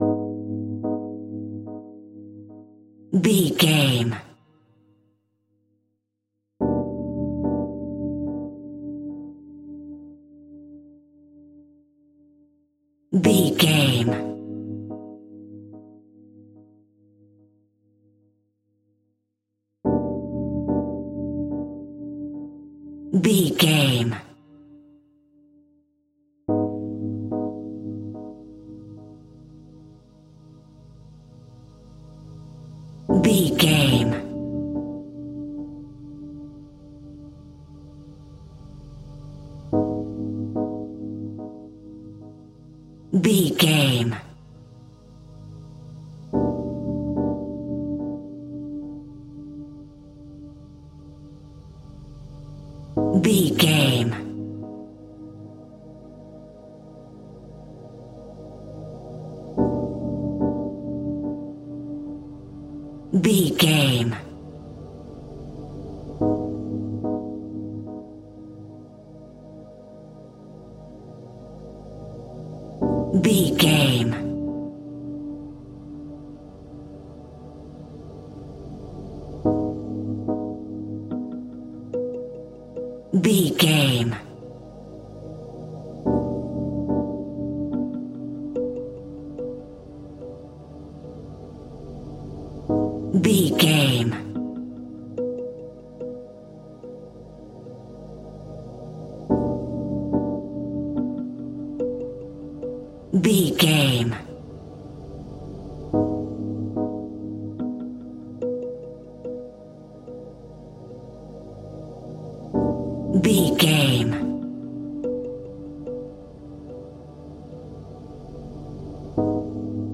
Survival horror
In-crescendo
Thriller
Aeolian/Minor
Slow
ominous
suspense
synthesizers
Synth Pads
atmospheres